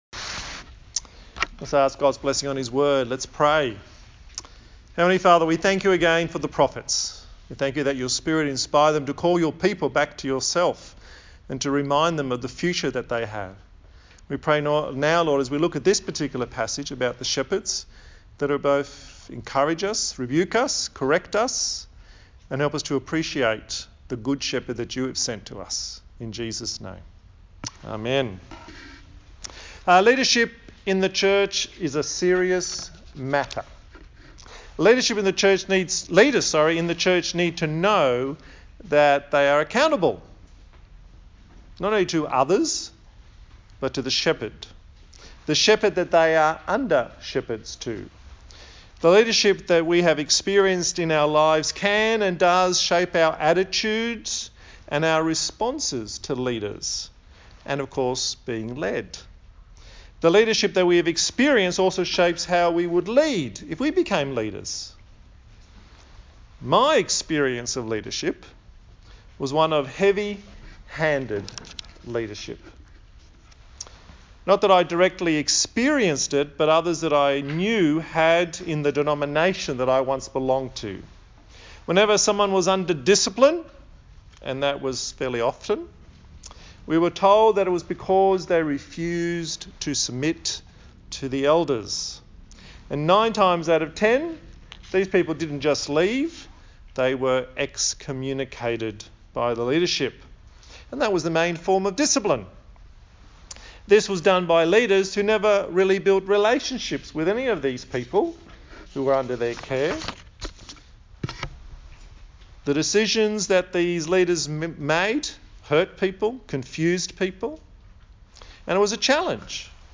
A sermon in the series on the book of Zechariah